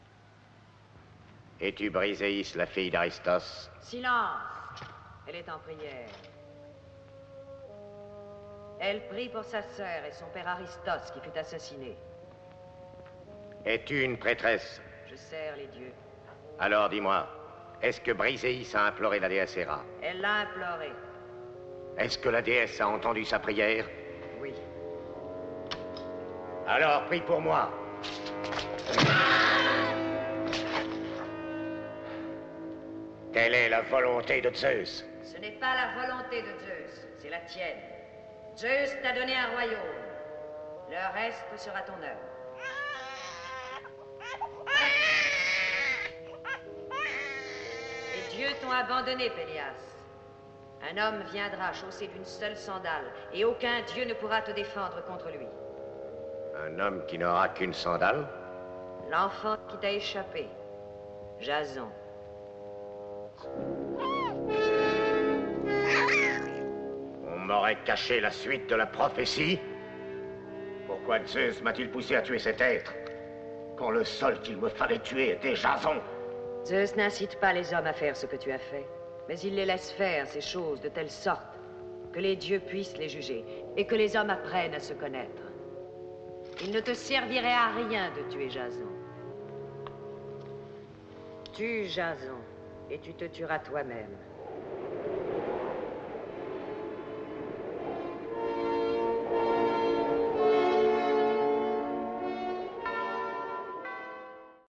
Quels personnages dialoguent ici ?
La fin de l’extrait nous révèle que l’homme s’appelle Pélias. Il prend la femme pour une prêtresse ; le visionnage nous montrera qu’il s’agit en fait d’Héra.